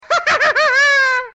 Play, download and share flapjacks laugh original sound button!!!!
flapjacks-laugh.mp3